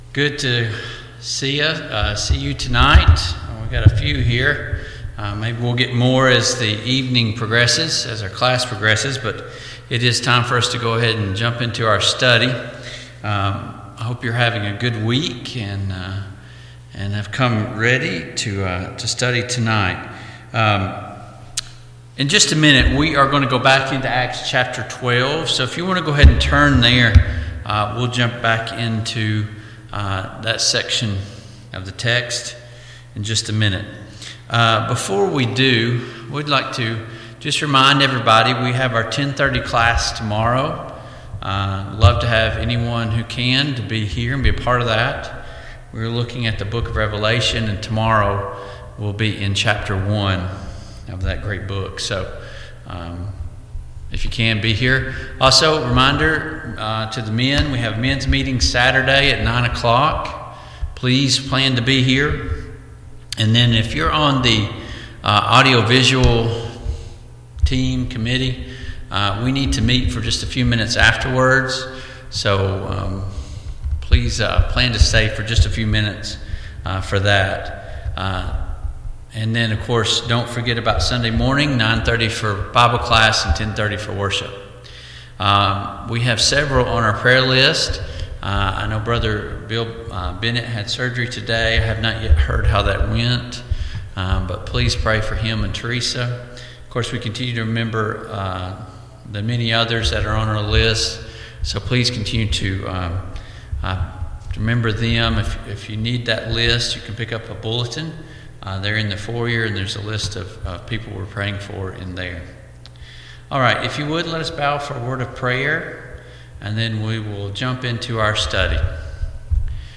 Passage: Acts 12:17-25 Service Type: Mid-Week Bible Study